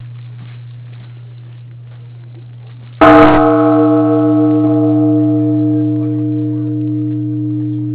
New Year's Eve bells
Click here, you can hear the sound of the bell (one time) recorded at the temple.